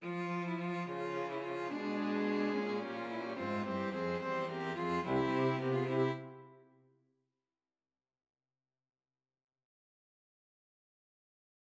베토벤, 소나타, 작품 14번 1번, 2악장, 1-8마디, 사중주 버전